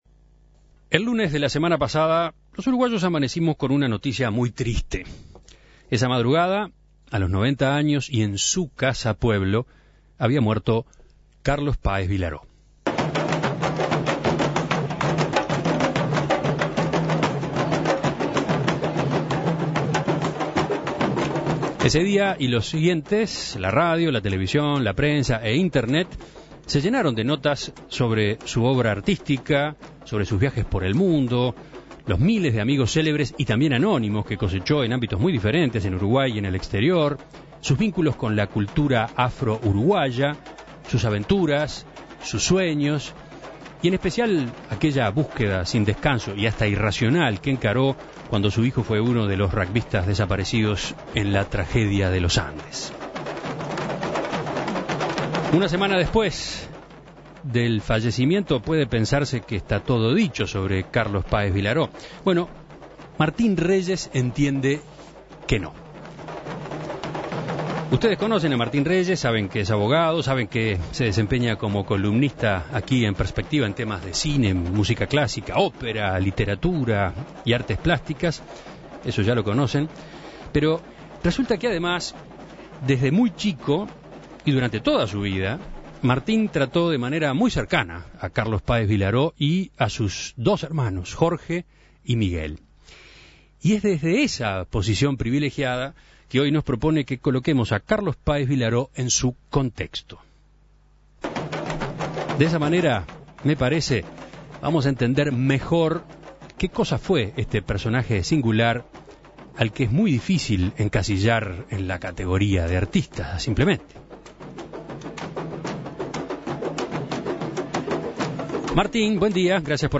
En la madrugada del domingo para el lunes, a los 90 años y en su Casapueblo, fallecía Carlos Páez Vilaró. En diálogo con En Perspectiva